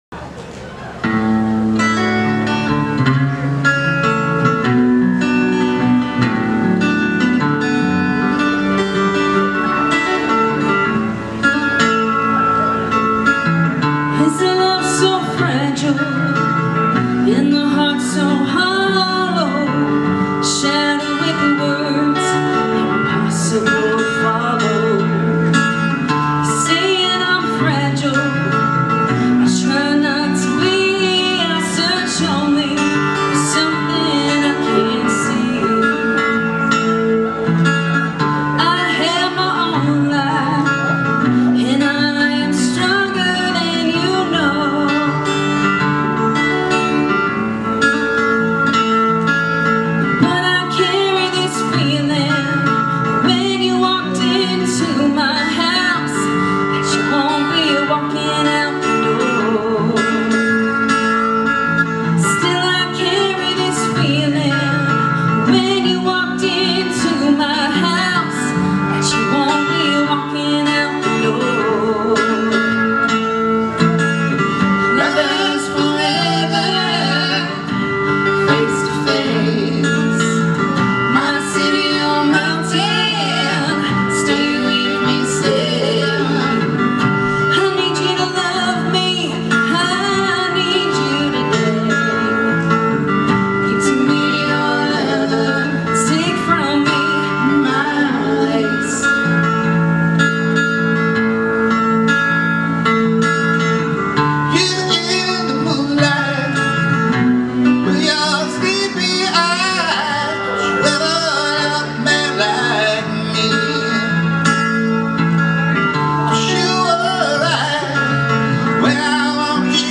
Live Anderson River Park 10/25